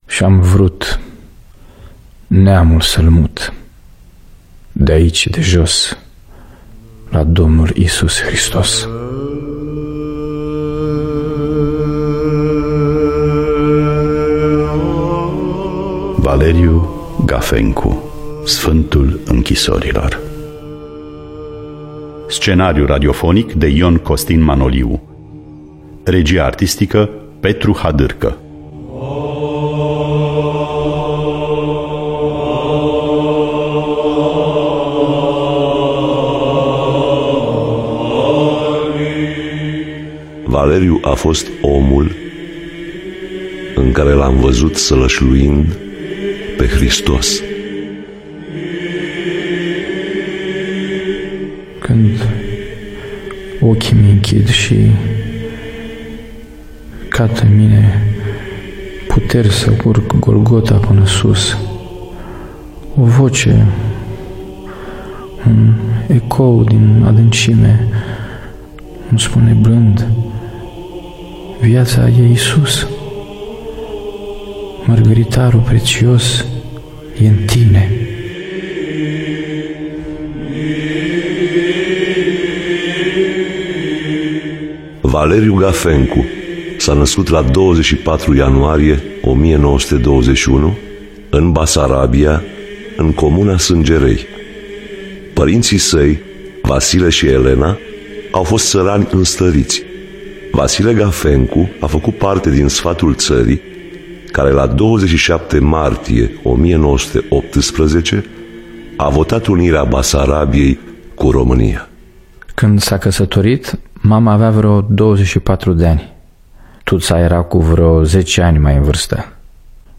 Biografii, Memorii: Valeriu Gafencu – Sfantul Inchisorilor (2011) – Teatru Radiofonic Online